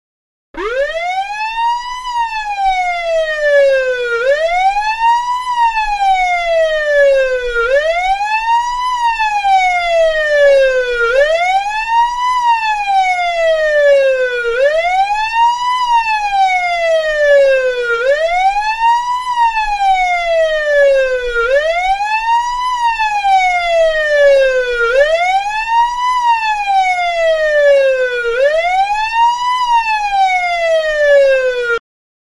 Звуки полицейской сирены
Звук сирены американской полиции